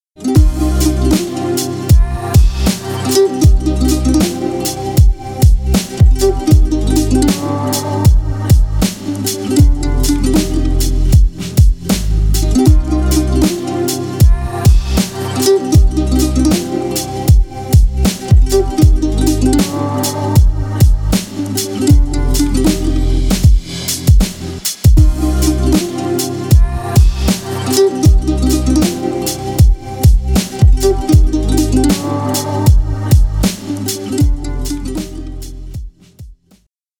Ремикс # Танцевальные
грустные